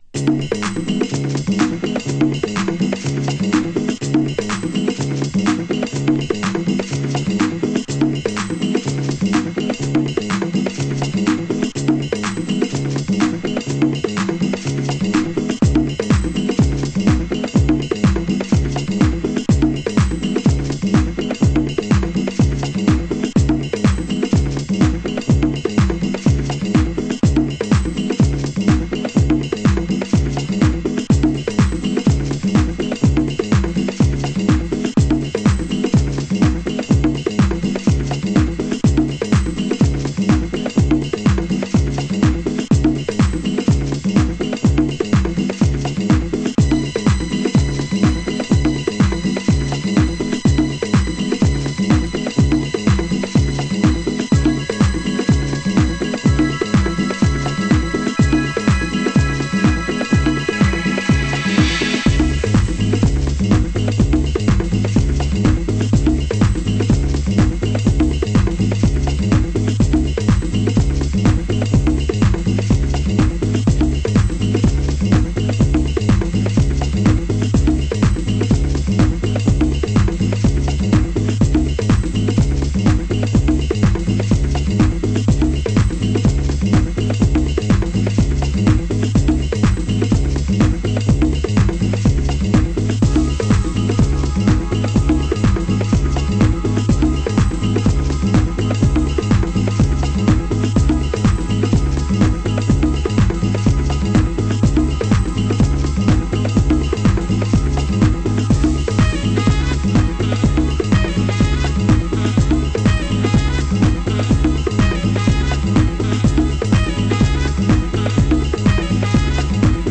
盤質：小傷により、少しチリパチノイズ有